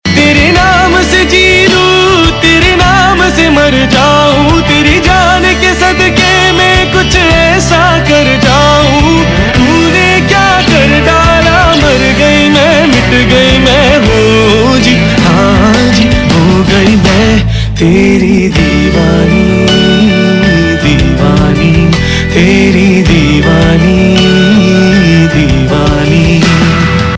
Pop Ringtones